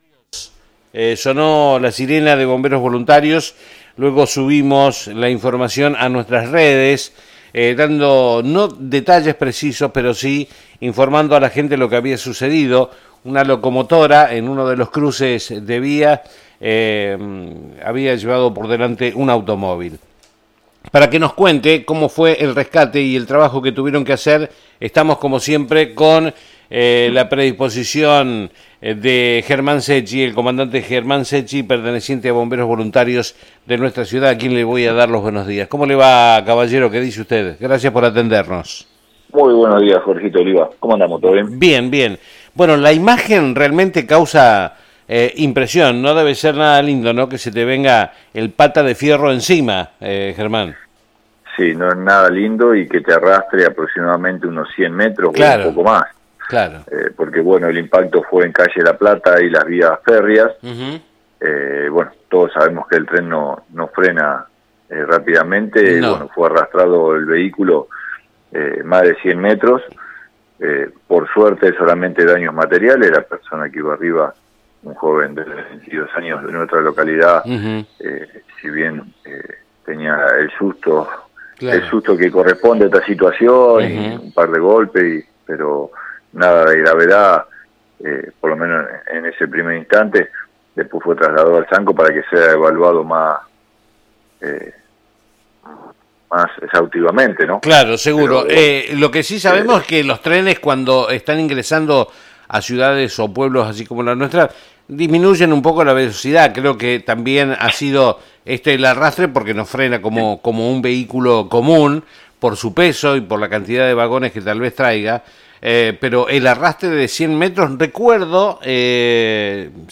Comunicación Telefónica